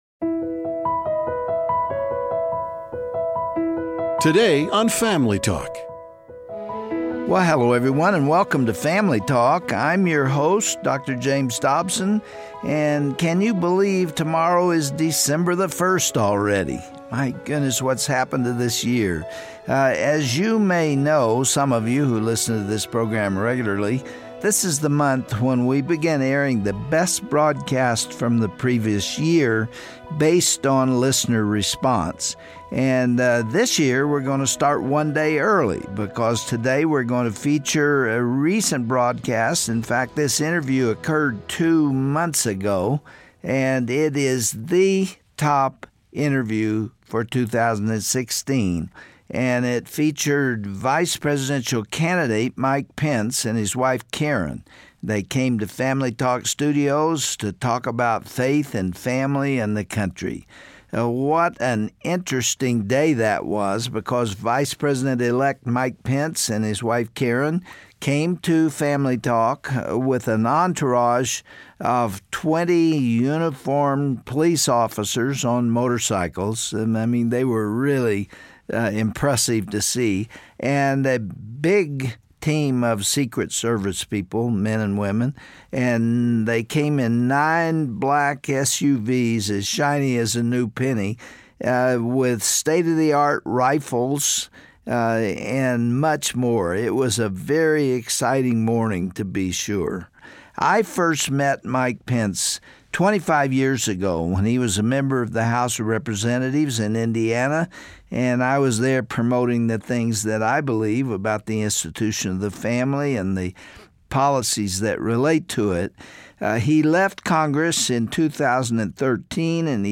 When they were on the campaign trail, Vice President Elect Mike and Karen Pence took a break to share their thoughts on faith and family with Dr. James Dobson. Their conversation kicks off our month long best of the best broadcasts.